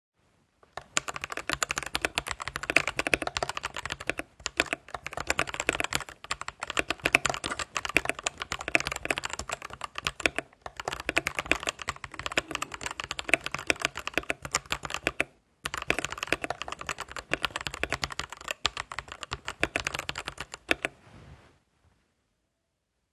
Switches & Geluidstest
Met de dempende lagen in het toetsenbord Heb je een geluidsprofiel die erg zacht kan zijn als je niet al te hard typet, maar als je wat fanatieker typet dan krijg je wel het stereotype lineaire geluidsprofiel.
Spatiebalk voelt en klinkt niet geweldig.
Geluidstest-Edifier-G4K.m4a